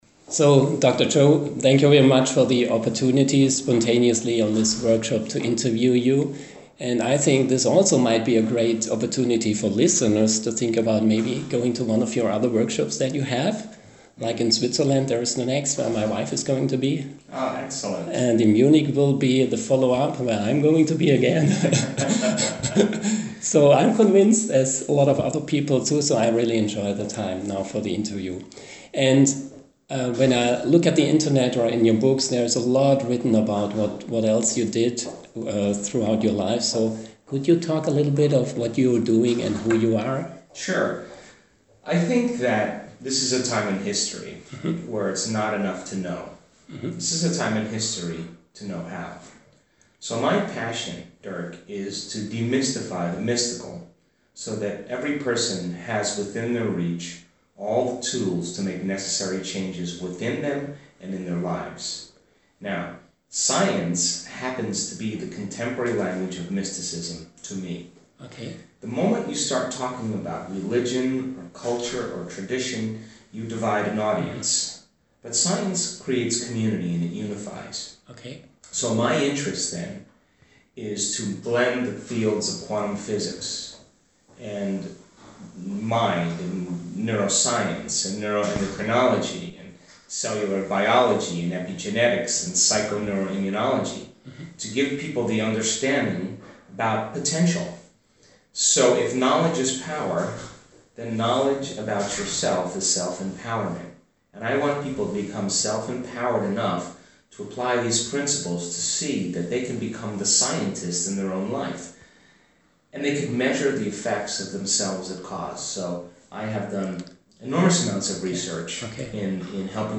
Dr. Joe Dispenza im Gespräch
interview-V1.mp3